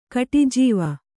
♪ kaṭijīva